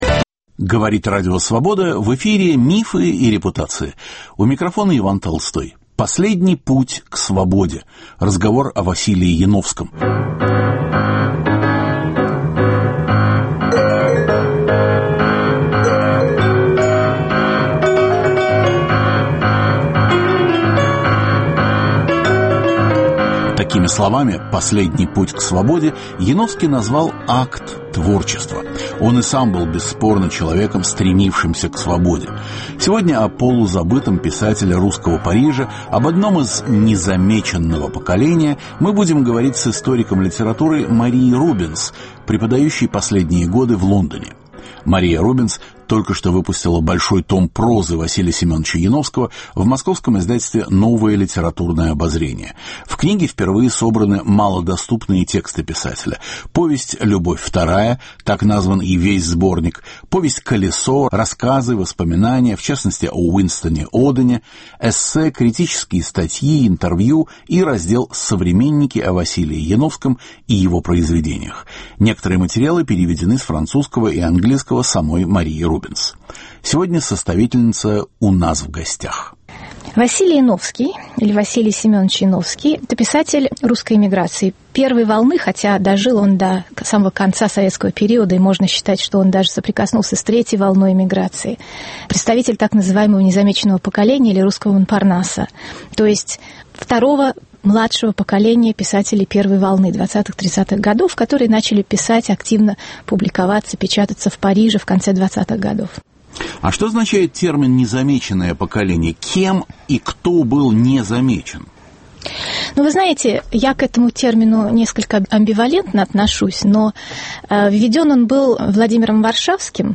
Разговор о Василии Яновском. В Московском издательстве НЛО вышел том прозы русского писателя из "незамеченного поколения" парижской эмиграции (1920-1930-е гг.).